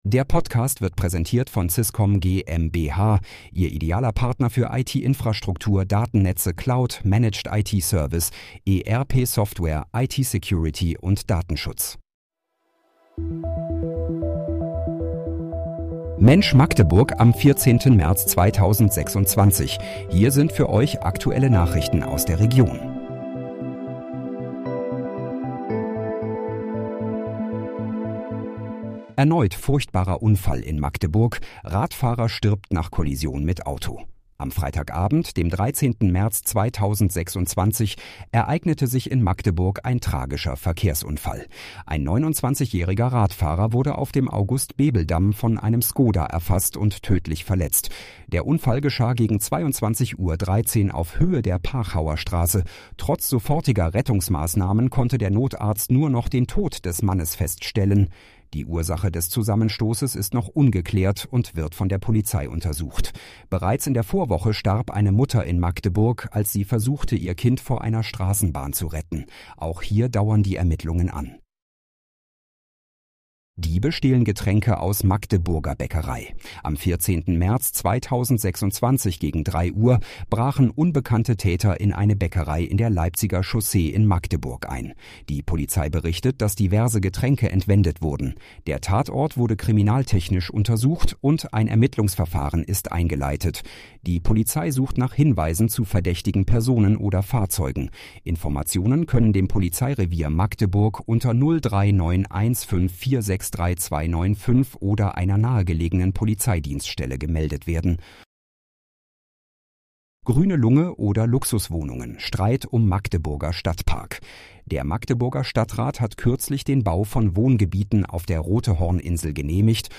Mensch, Magdeburg: Aktuelle Nachrichten vom 14.03.2026, erstellt mit KI-Unterstützung